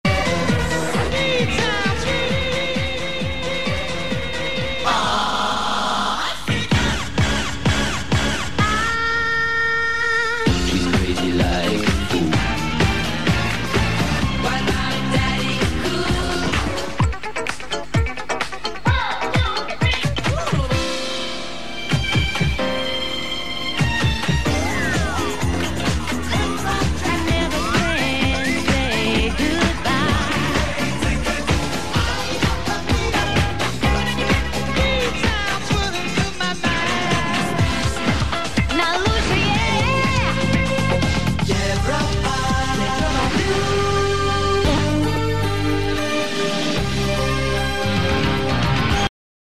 Предлагаю вашему вниманию музыкальные заставки придумывал и записывал сам на магнитоле "Panasonic CT980". Оцифровка с кассет.
заставки